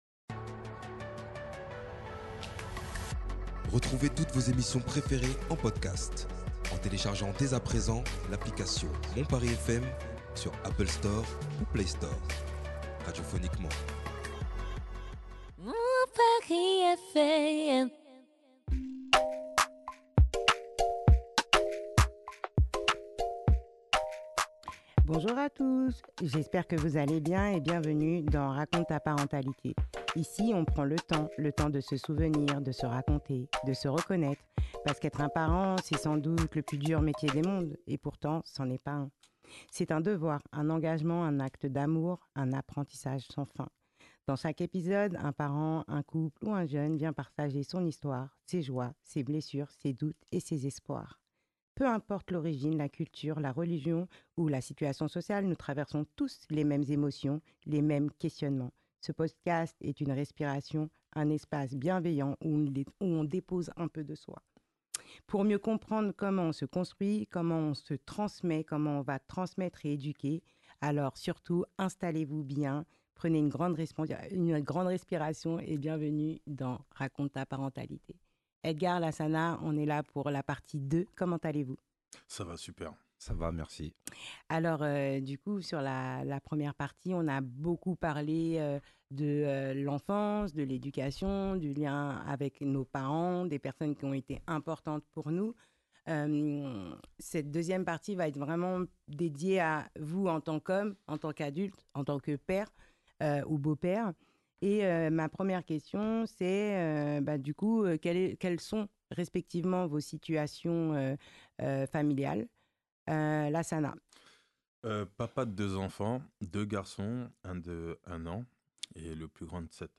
À travers leurs récits, ils nous ouvrent les portes de leur enfance, de leur éducation, et nous livrent la manière dont ces expériences ont façonné les hommes et les pères qu’ils sont devenus aujourd’hui. Alors, prenez une bonne inspiration, servez-vous une tasse de thé ????, installez-vous confortablement… et laissez-vous porter par cette conversation sincère, profonde et inspirant